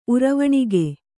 ♪ uravaṇige